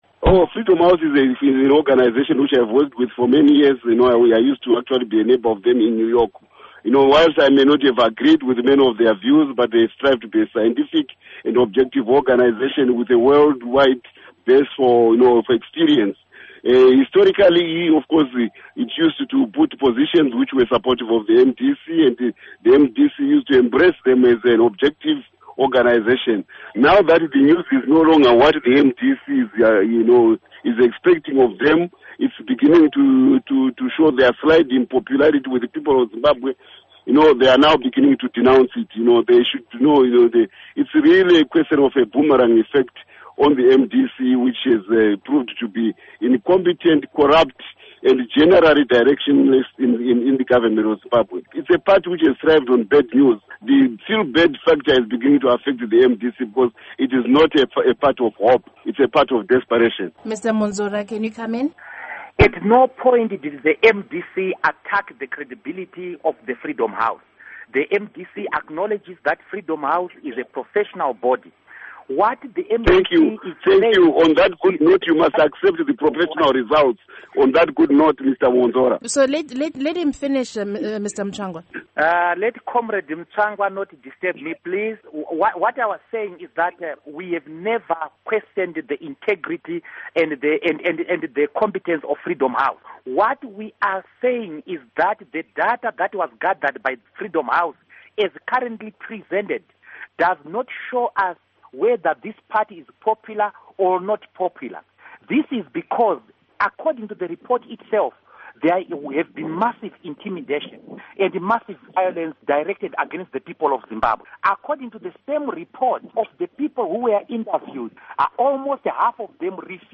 Panel Discussion with Chris Mutsvangwa & Douglas Mwonzora